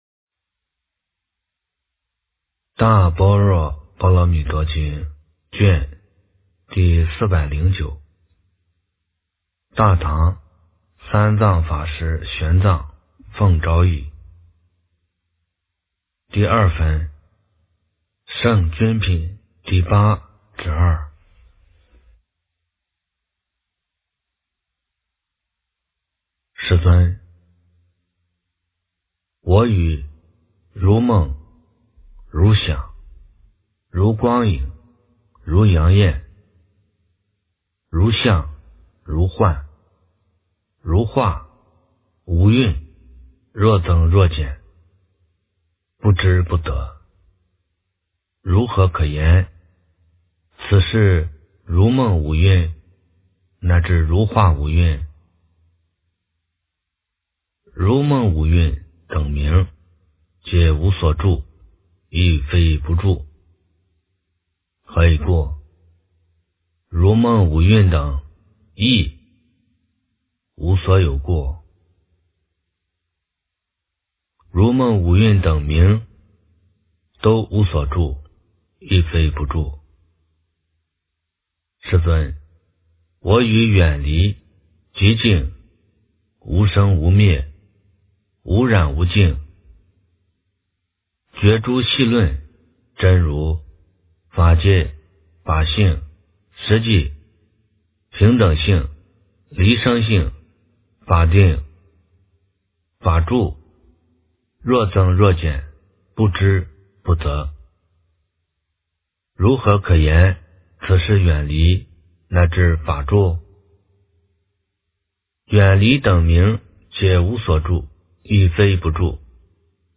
大般若波罗蜜多经409卷 - 诵经 - 云佛论坛